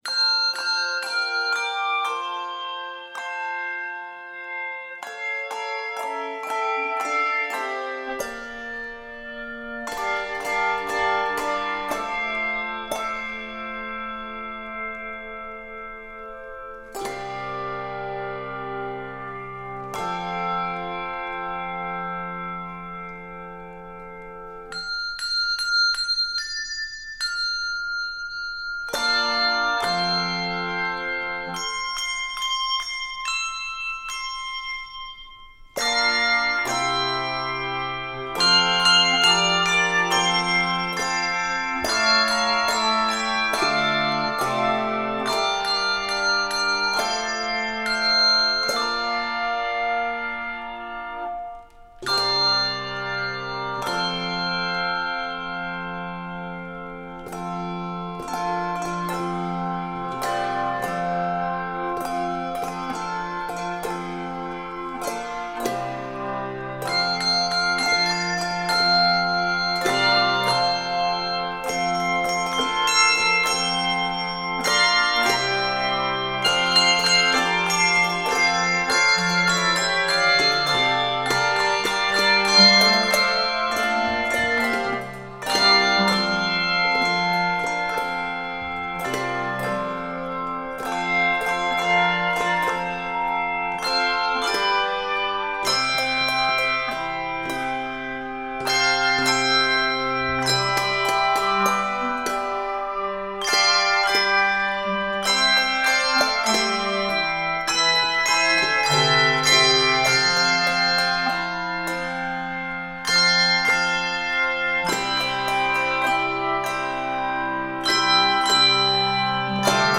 Keys of F Major and C Major.